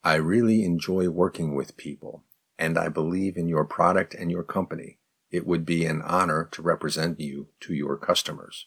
02_advanced_response_slow.mp3